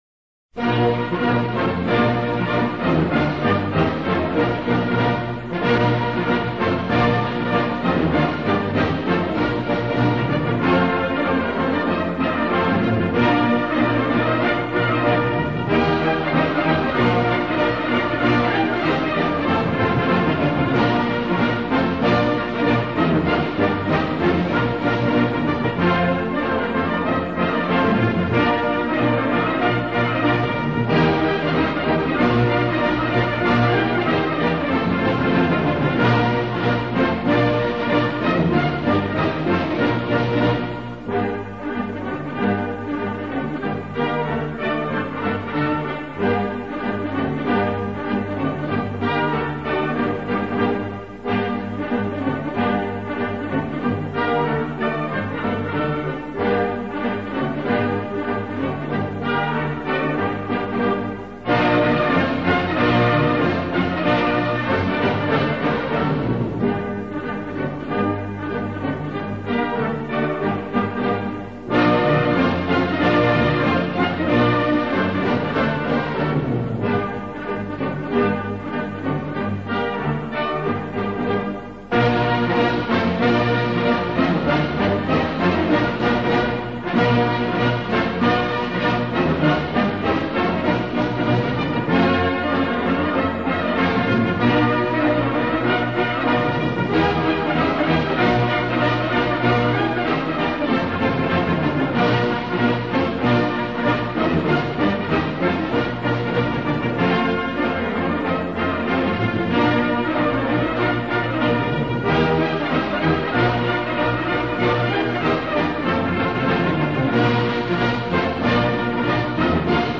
Послушайте, как в современном исполнении звучит этот старый торжественно-грозный марш: